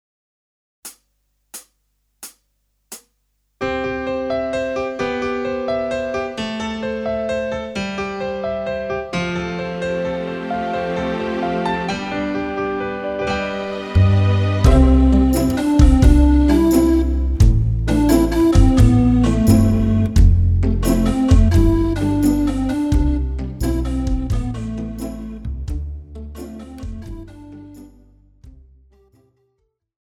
Žánr: Pop
BPM: 88
Key: C
MP3 ukázka s ML